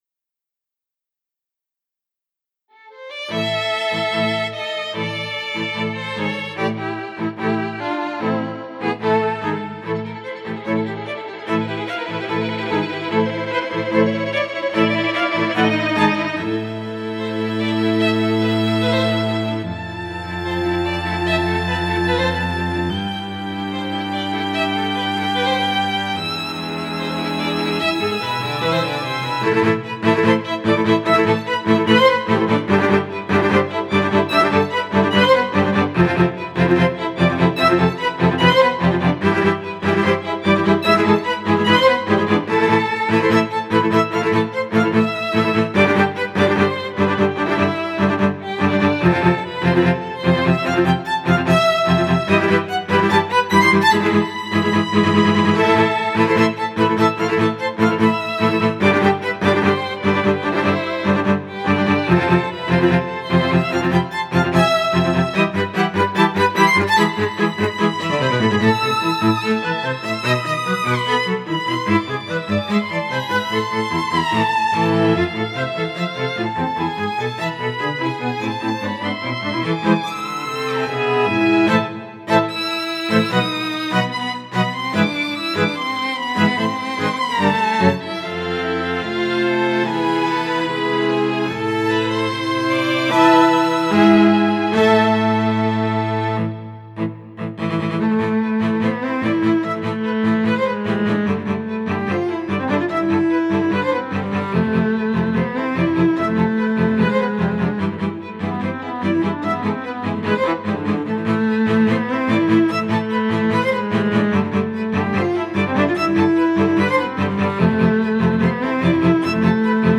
新弦音源導入記念に書き下ろしっ